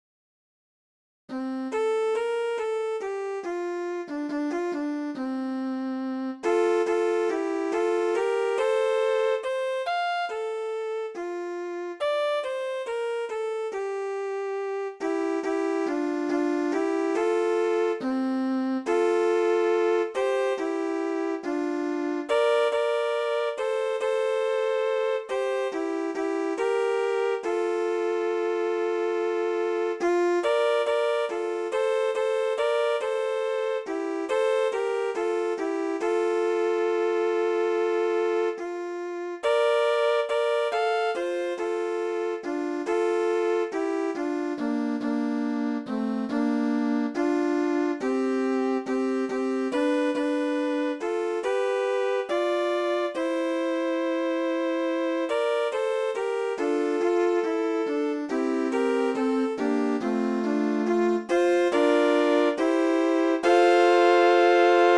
THÁNH CA